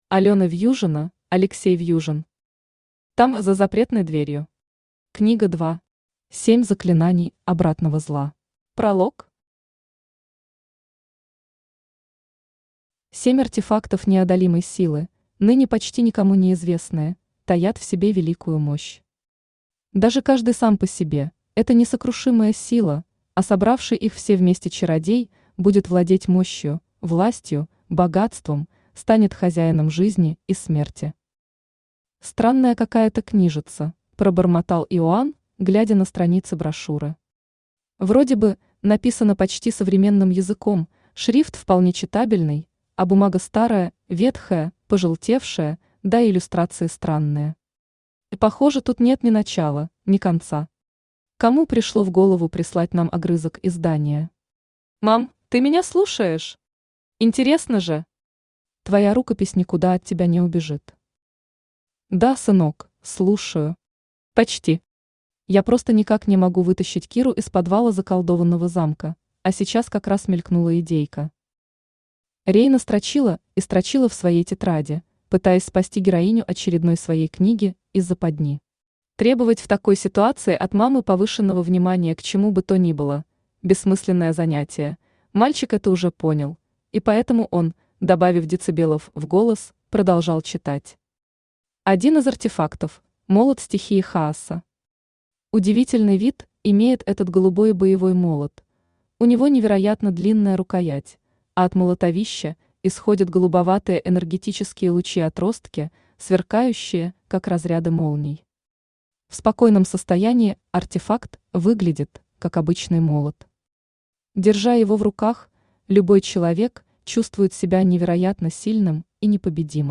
Аудиокнига Там, за запретной дверью… Книга 2. Семь заклинаний обратного зла | Библиотека аудиокниг
Семь заклинаний обратного зла Автор Алена Вьюжина Читает аудиокнигу Авточтец ЛитРес.